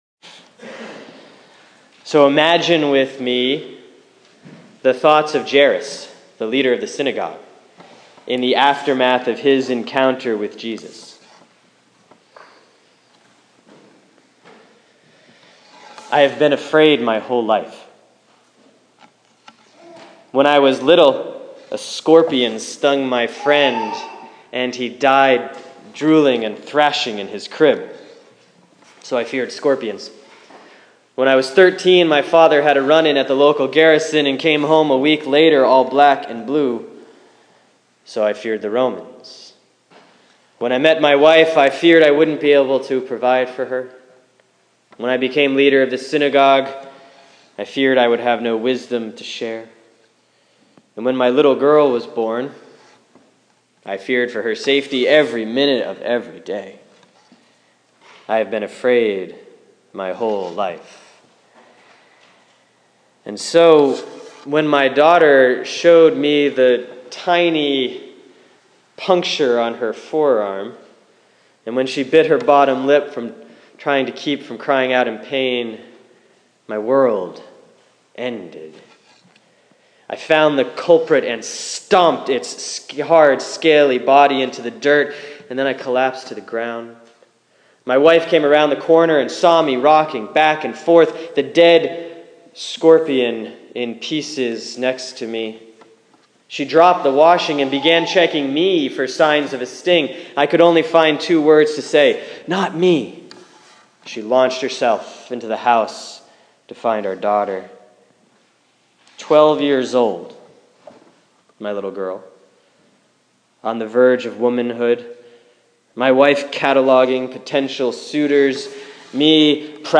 A story sermon about fear and trust, told from the perspective of Jairus, the leader of the synagogue in today's Gospel lesson.